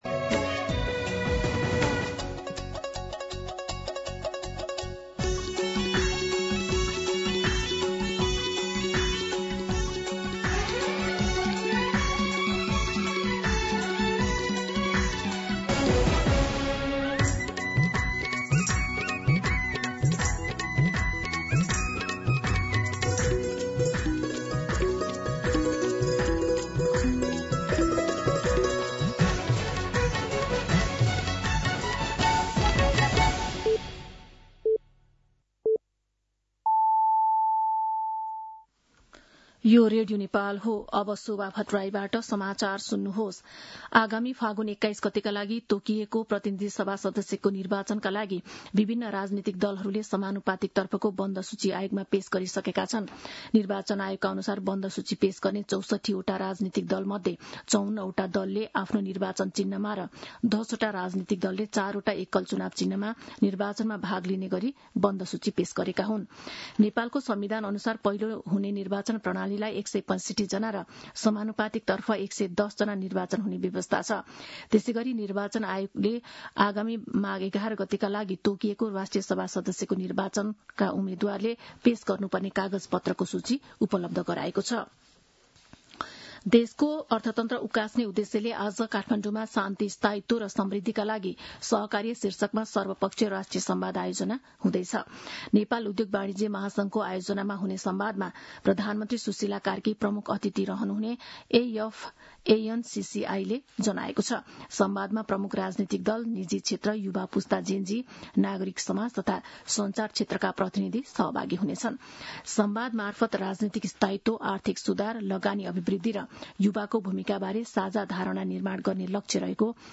दिउँसो १ बजेको नेपाली समाचार : १६ पुष , २०८२
1pm-news-9-16.mp3